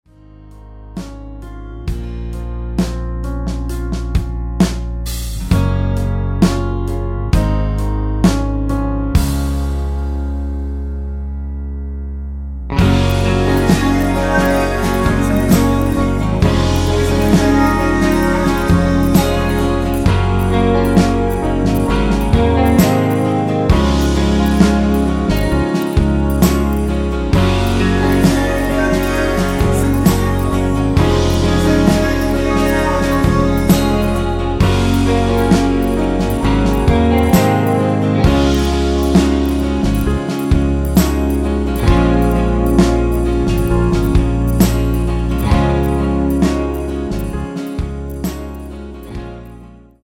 원키에서(-1)내린 코러스 포함된 MR입니다.
가사의 노란색 부분에 코러스가 들어갔습니다.(가사및 미리듣기 참조)
Db
앞부분30초, 뒷부분30초씩 편집해서 올려 드리고 있습니다.
중간에 음이 끈어지고 다시 나오는 이유는